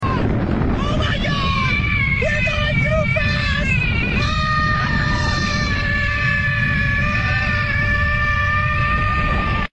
The Cat vs Roller Coaster sound button is from our meme soundboard library